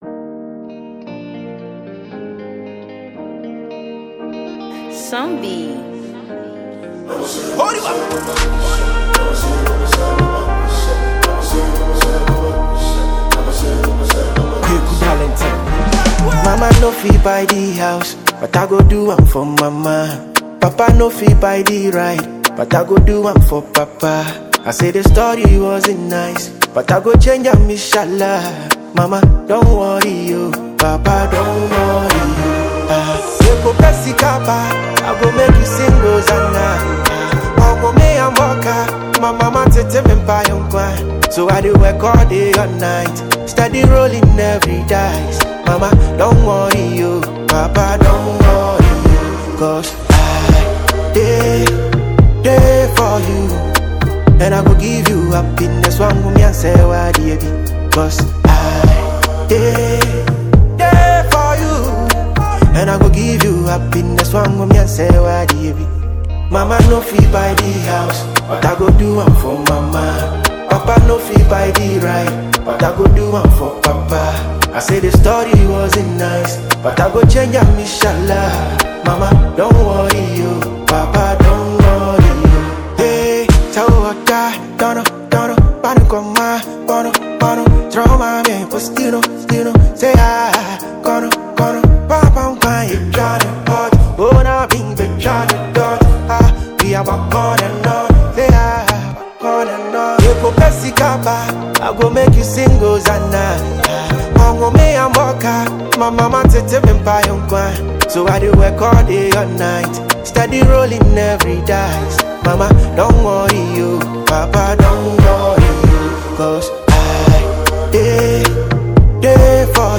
a warm, modern soundscape that elevates the message